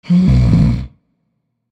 inhale.ogg.mp3